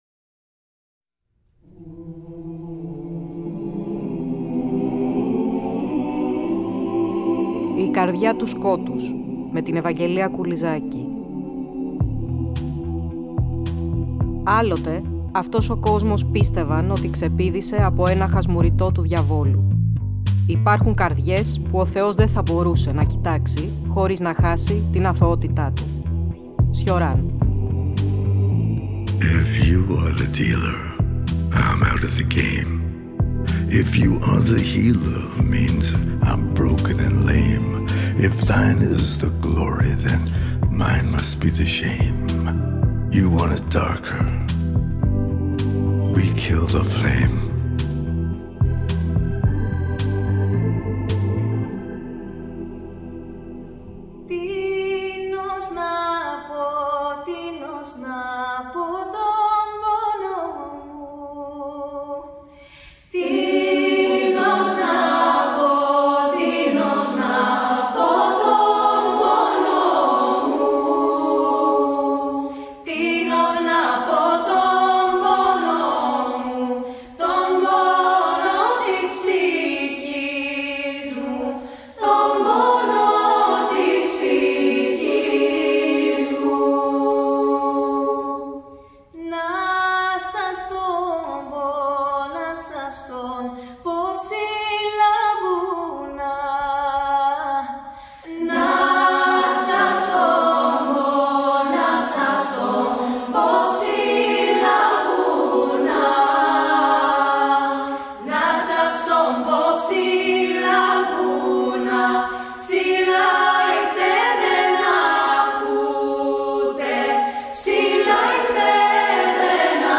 Στην εκπομπή ακούγονται και δύο σύντομα αποσπάσματα από τη μικρού μήκους ταινία ”Ακόμα;” (1994)